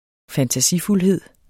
Udtale [ fantaˈsifulˌheðˀ ]